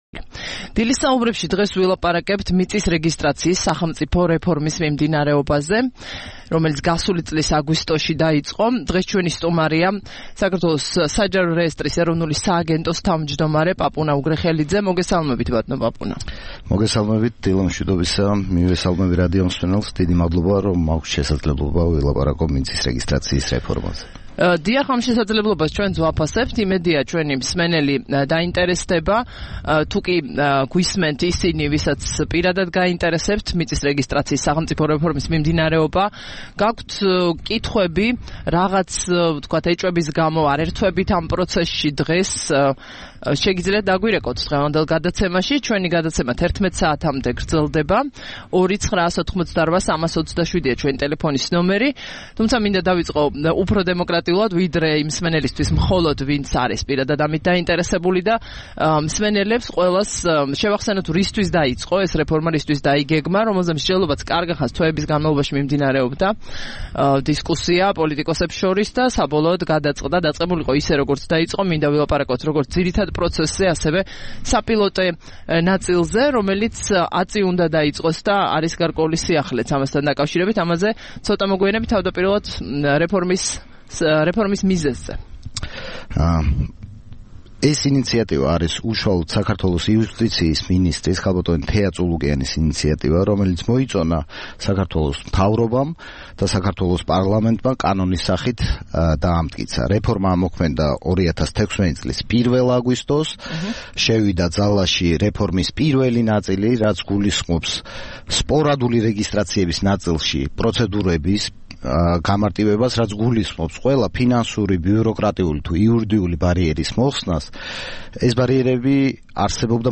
სტუმრად ჩვენს ეთერში: პაპუნა უგრეხელიძე
6 აპრილს რადიო თავისუფლების "დილის საუბრების" სტუმარი იყო პაპუნა უგრეხელიძე, საჯარო რეესტრის ეროვნული სააგენტოს თავმჯდომარე.